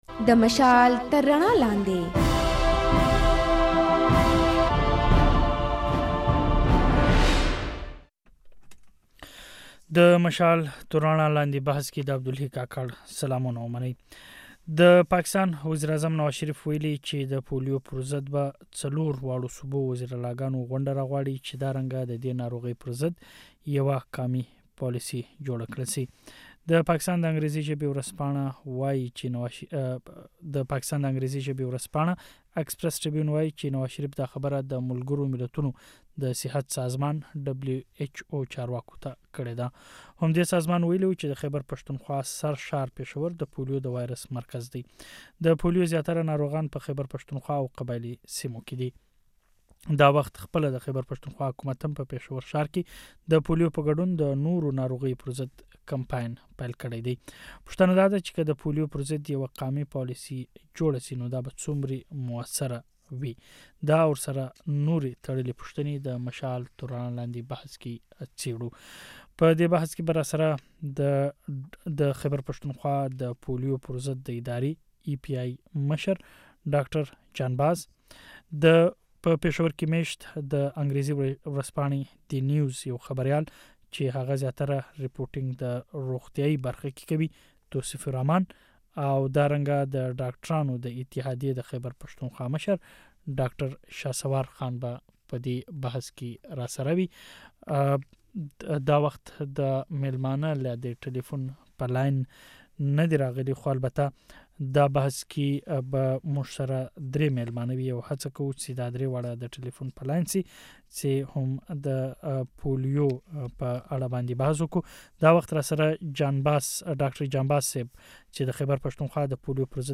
دا د مشال راډیو د ځانګړي بحث او شننو اوونیزې خپرونې پاڼه ده.